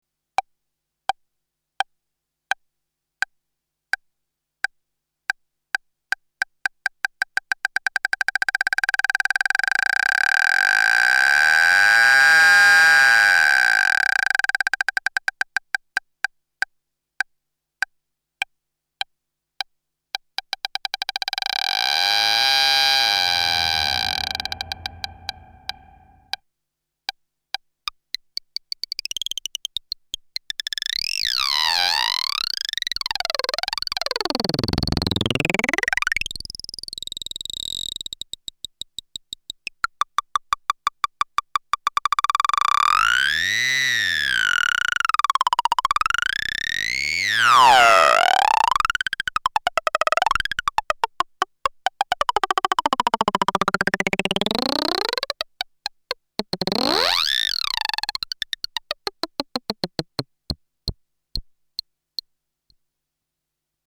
Хрюкает...